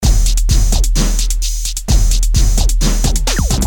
Electro rythm - 130bpm 29